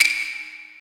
drum-hitwhistle.mp3